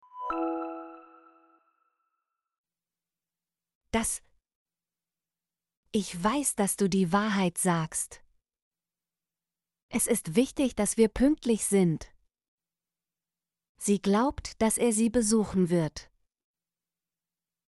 dass - Example Sentences & Pronunciation, German Frequency List